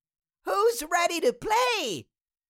Cartoon Little Child, Voice, Who Is Ready To Play Sound Effect Download | Gfx Sounds
Cartoon-little-child-voice-who-is-ready-to-play.mp3